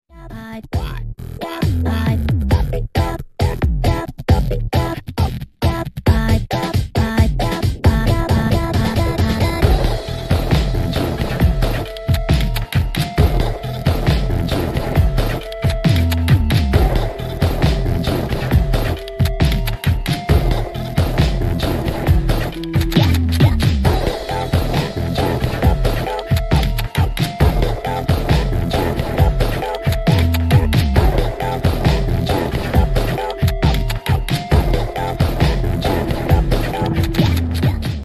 Who know gunshots in this mod could be such a BANGER?!